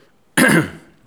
raclement-gorge_02.wav